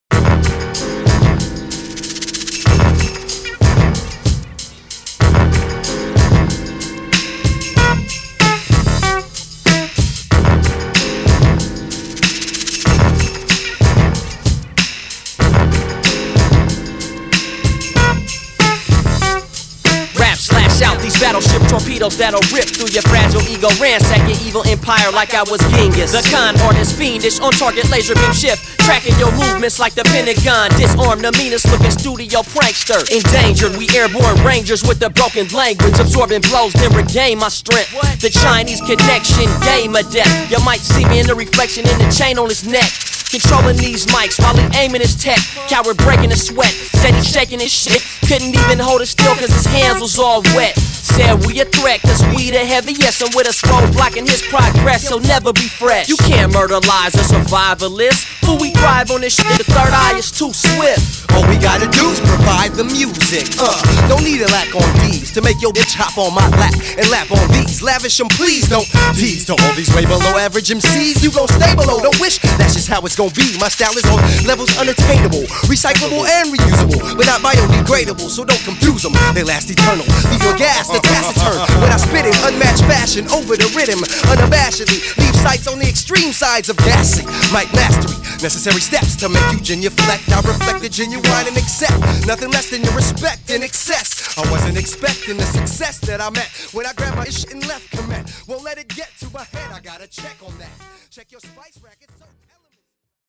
lends scratching talents throughout the record